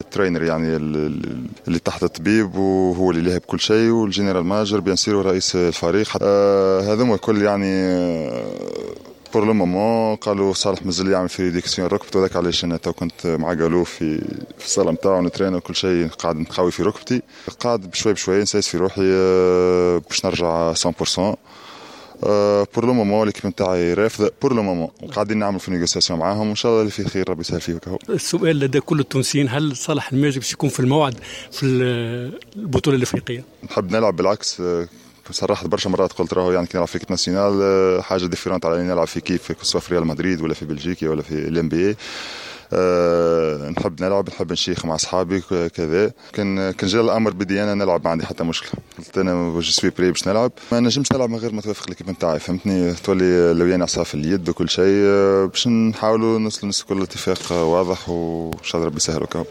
و أوضح صالح الماجري في تصريح